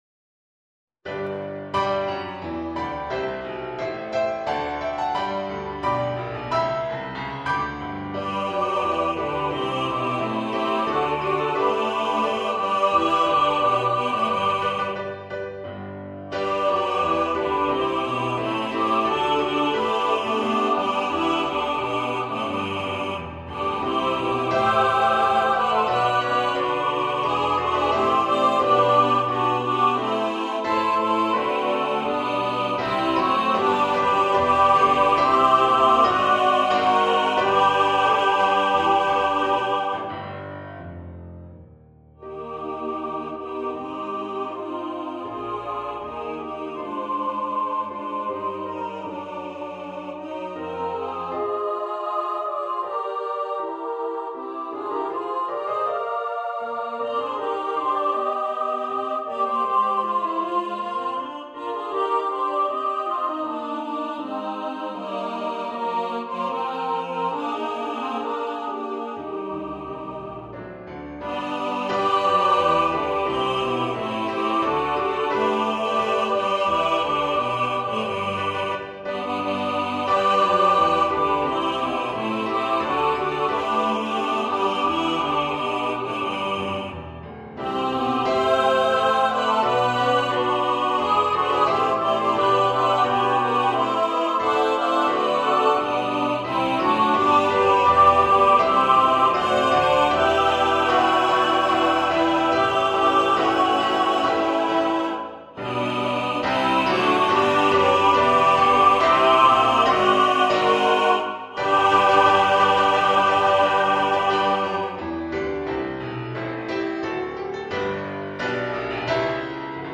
for SATB choir
A Carol for Christmas for choir and orchestra or piano.